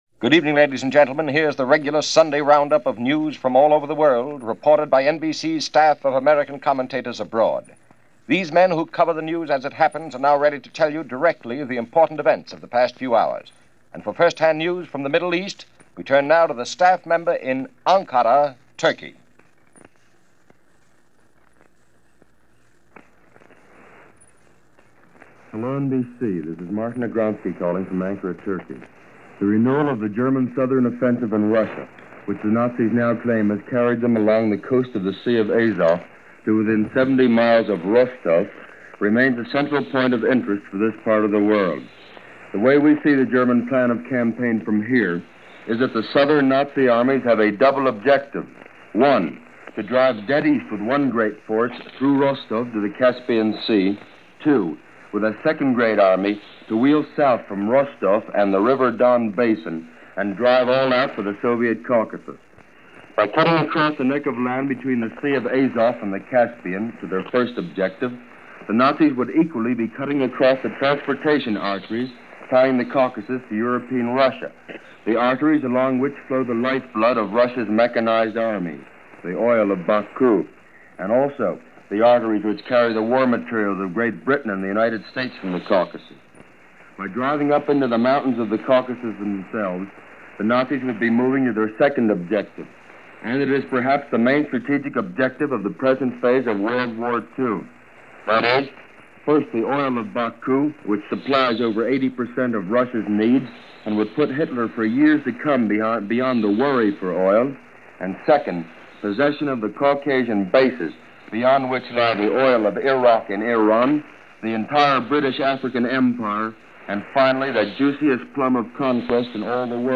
October 19, 1941 - All Eyes On Moscow - German army advances into Russia, approaching Kiev on the way to Moscow - News Of this day, 1941.